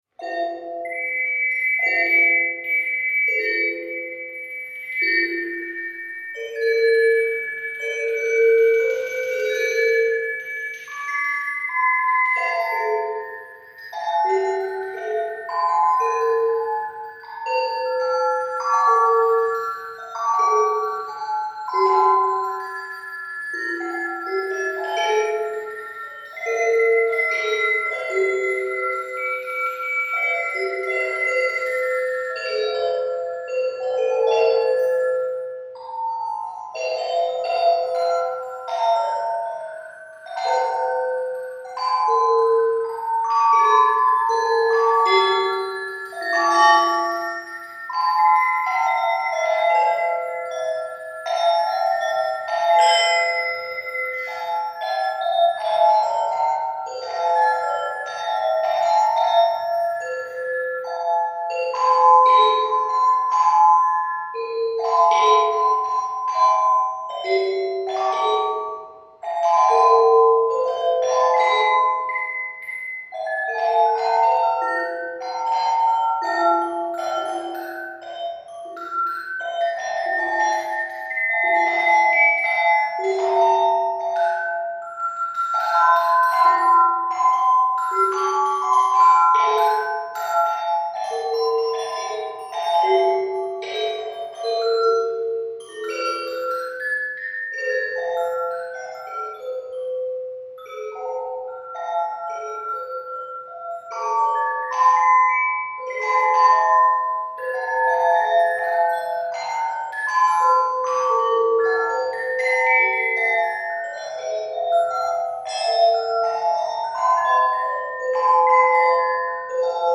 Made in October 2008 using a toy glock, and recorded/multitracked using my laptop, to welcome a new baby ...